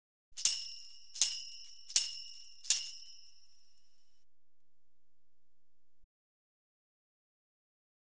Tambourine Hits Sound Effect
Download a high-quality tambourine hits sound effect.
tambourine-hits.wav